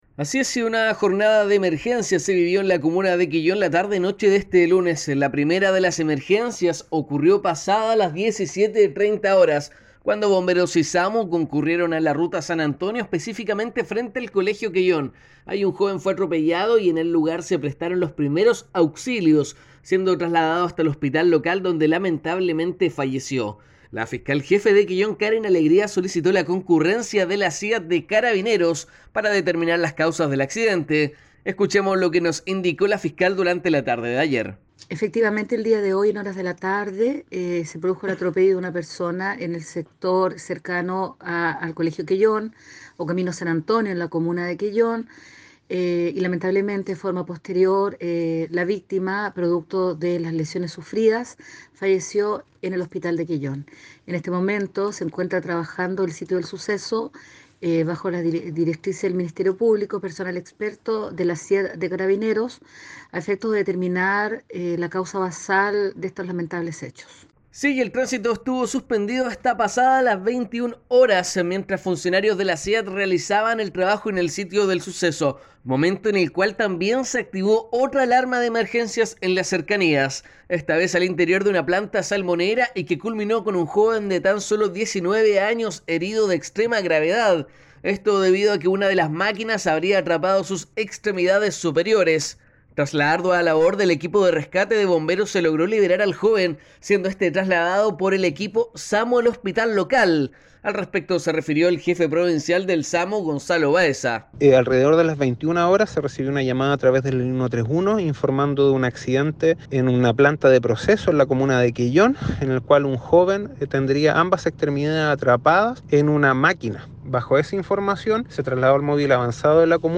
Contacto noticioso